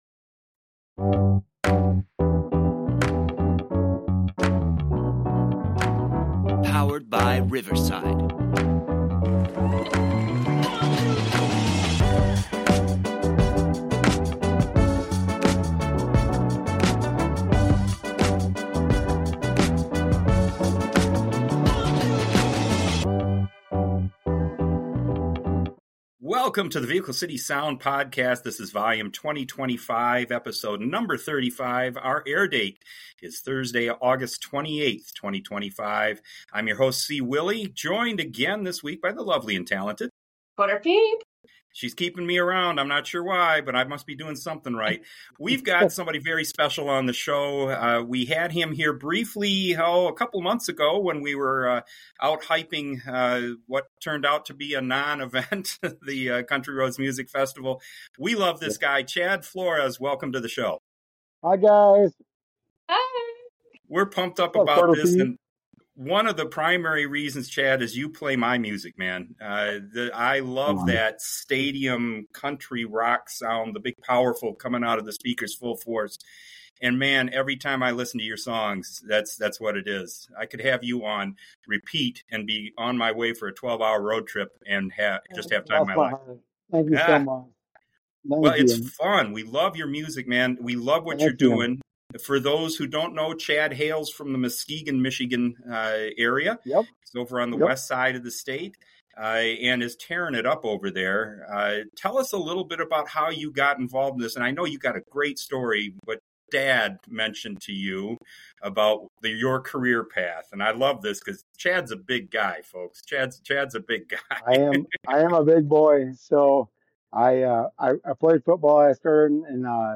His Stadium country rock sound resonates with a truly devoted fan base.&nbsp